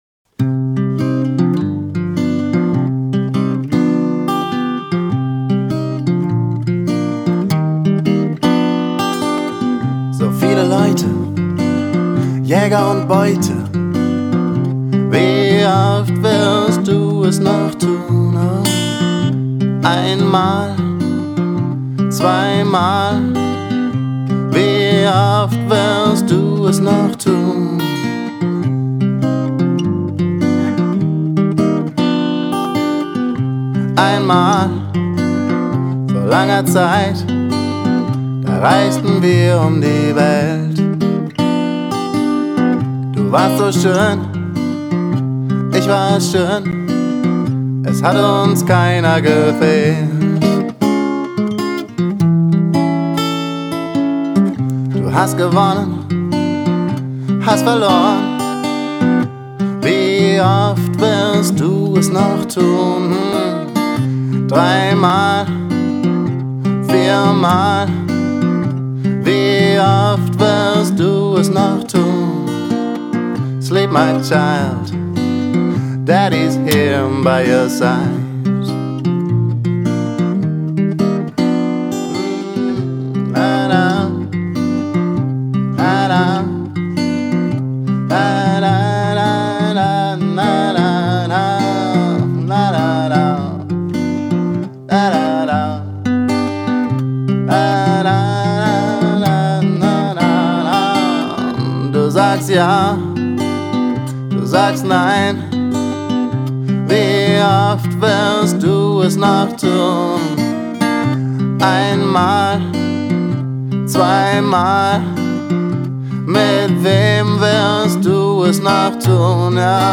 Chanson, g + voc, Song # 82, mp3